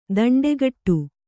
♪ daṇḍegaṭṭu